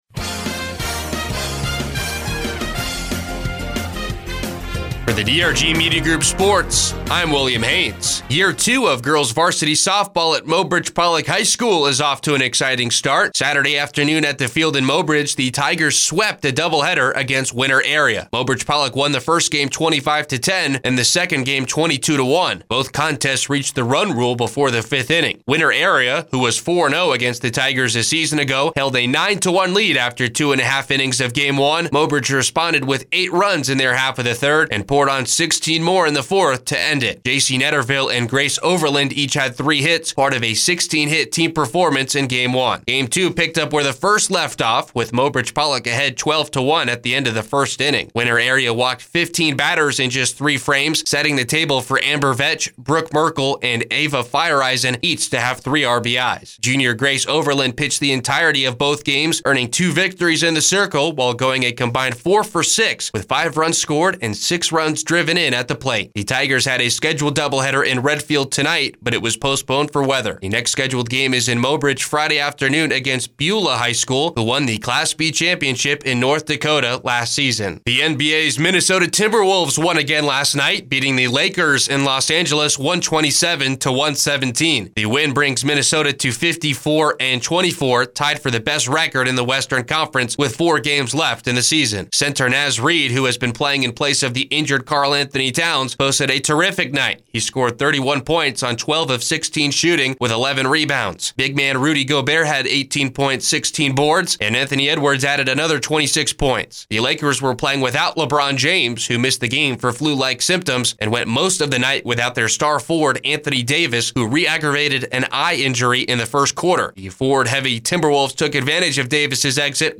4-8-24-midday-sports.mp3